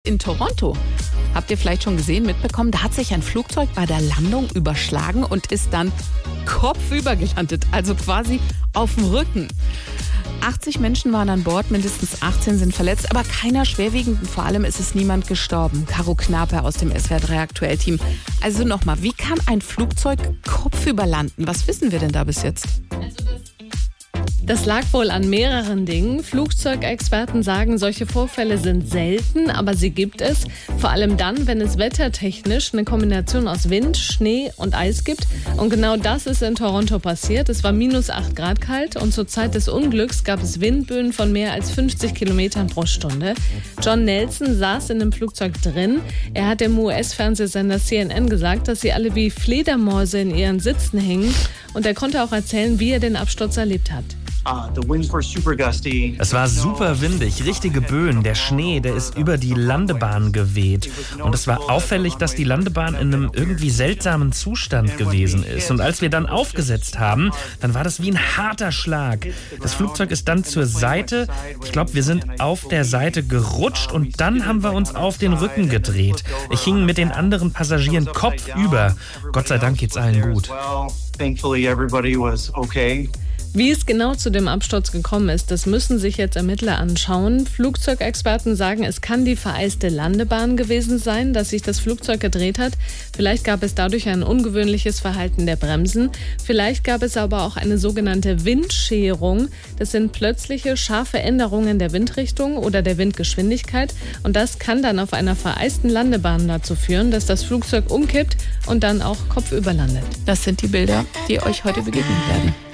3. Nachrichten